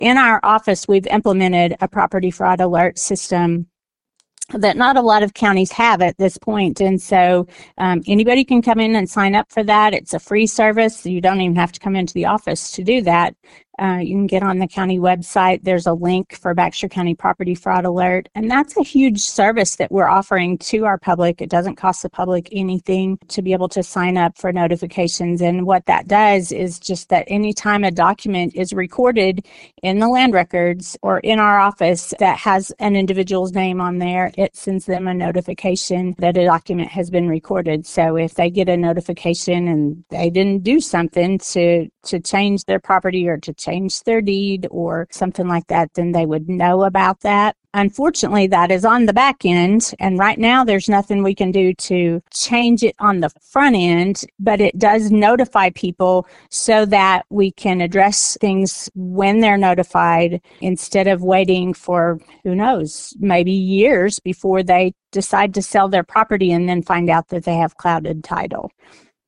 Baxter County Clerk Canda Reese spoke with KTLO News and says the goal is to give the property owners an early warning if someone attempts a fraudulent property transfer.